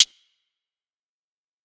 HiHat (34).wav